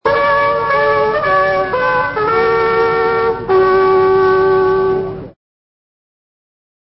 as a ringtone